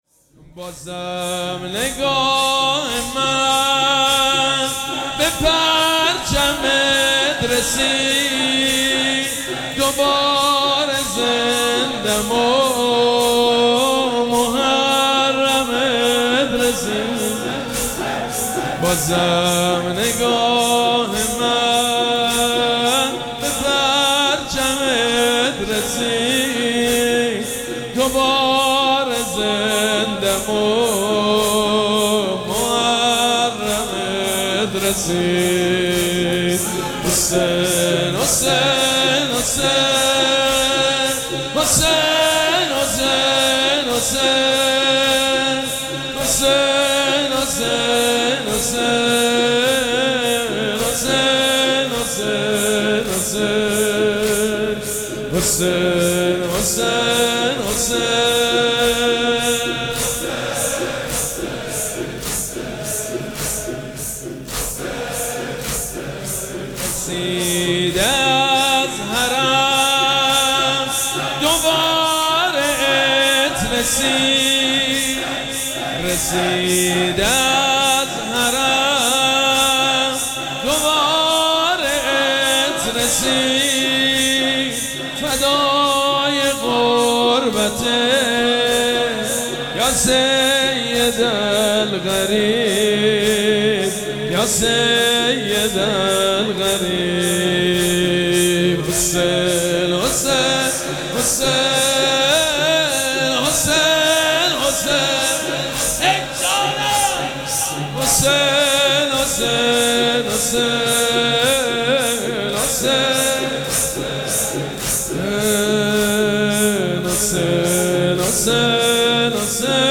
مراسم عزاداری شب اول محرم الحرام ۱۴۴۷
مداح
حاج سید مجید بنی فاطمه